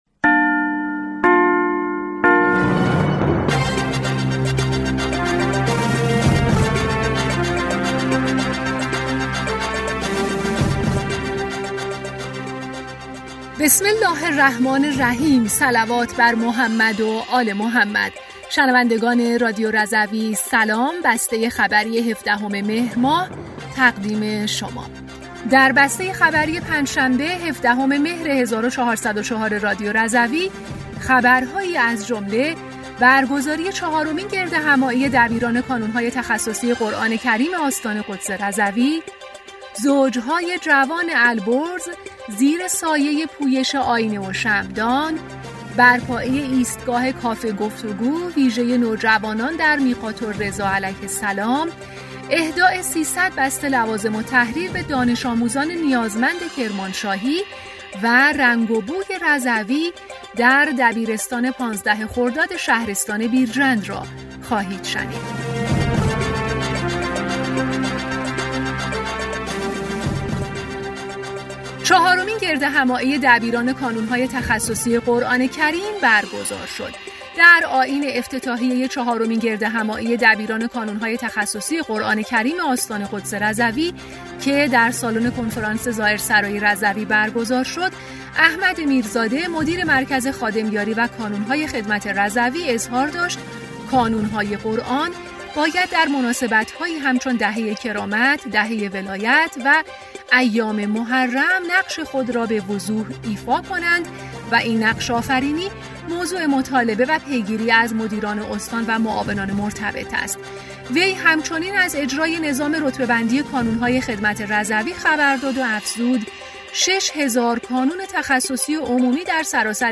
بسته خبری ۱۷ مهر ۱۴۰۴ رادیو رضوی؛